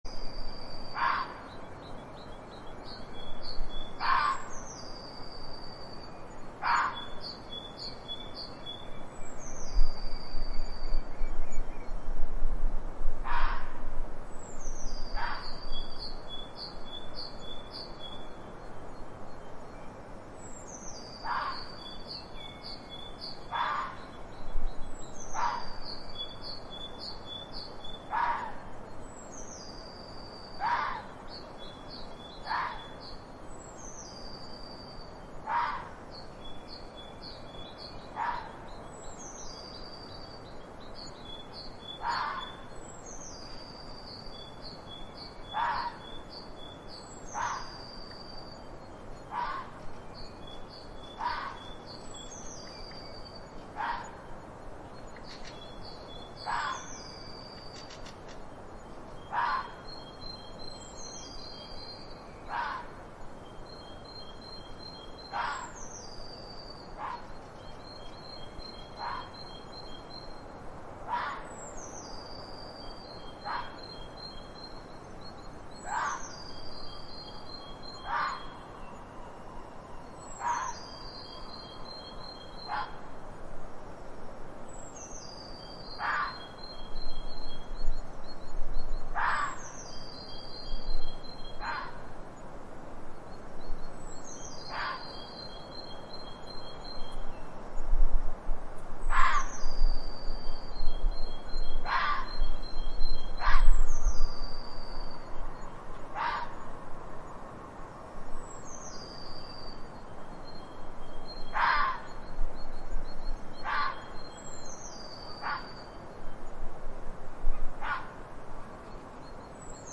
Geräusche
Fuchs bellen im Winter
Das Bellen eines Fuchses ist ein hohes, durchdringendes Geräusch, das oft in der Dämmerung oder nachts zu hören ist.
fox-in-spring-night-59832-von-Pixabay.mp3